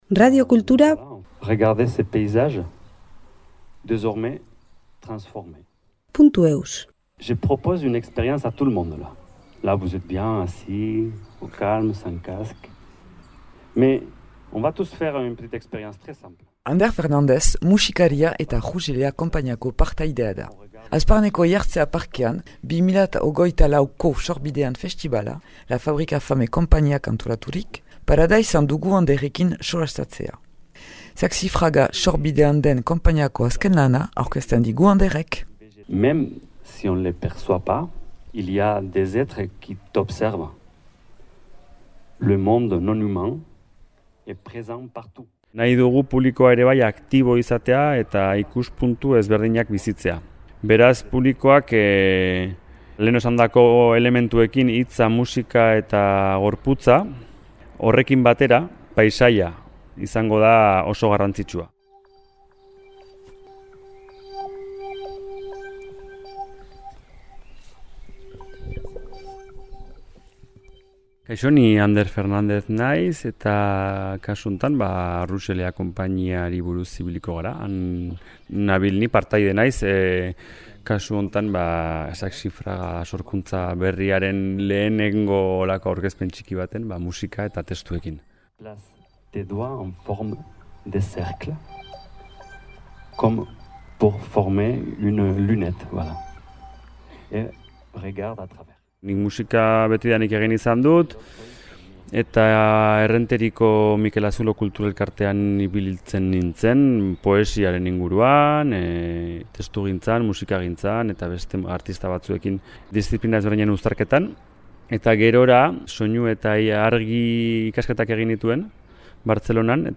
Hazparneko Eihartzea parkean, 2024ko «Sorbidean festibala»